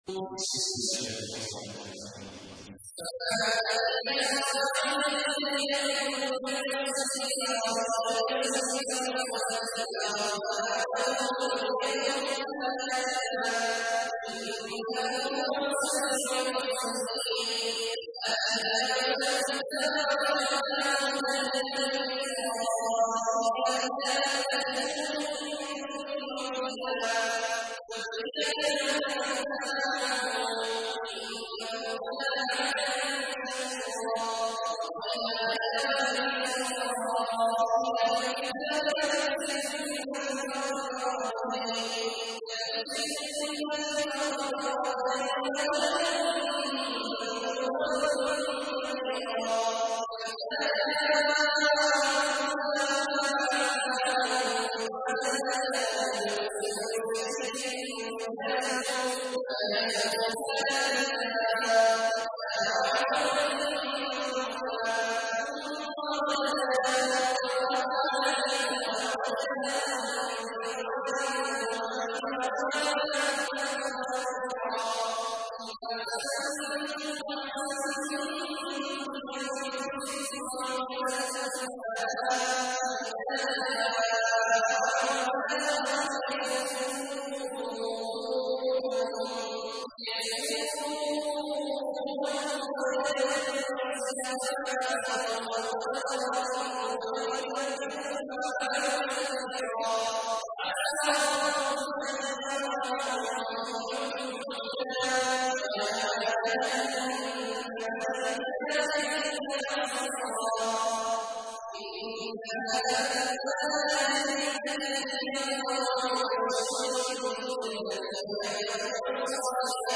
تحميل : 17. سورة الإسراء / القارئ عبد الله عواد الجهني / القرآن الكريم / موقع يا حسين